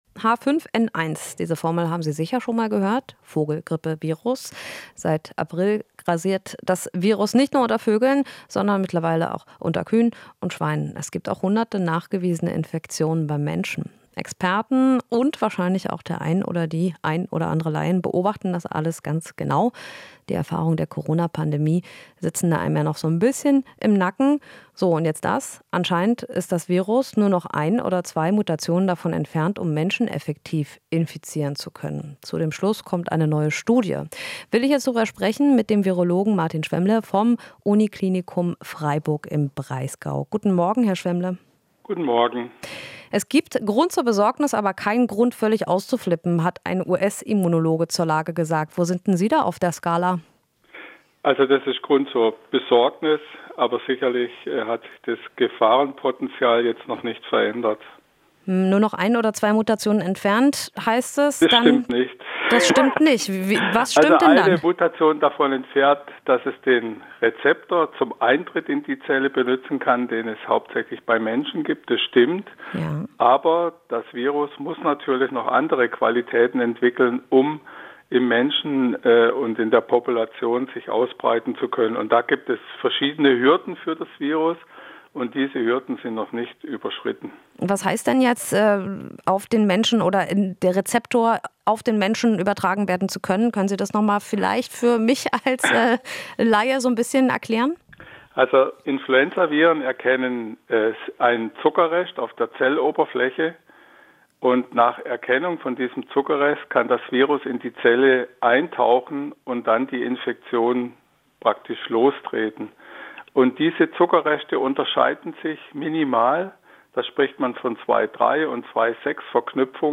Interview - Virologe zu H5N1: "Gefahr für die Bevölkerung ist noch gering"